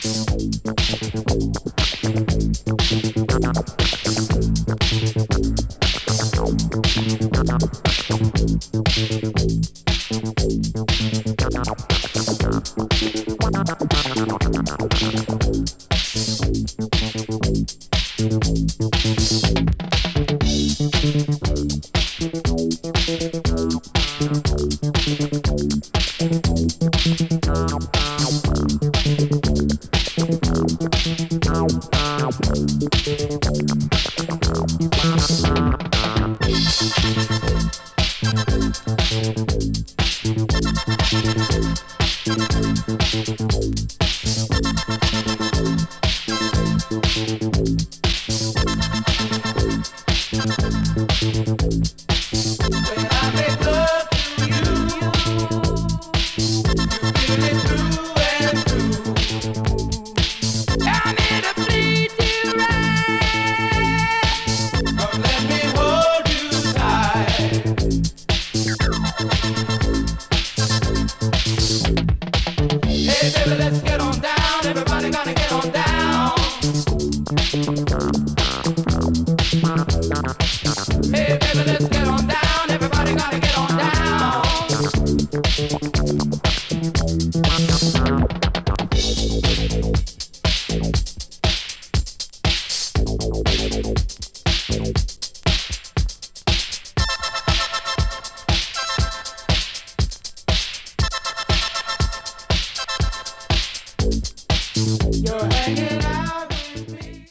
ディスコ・エディット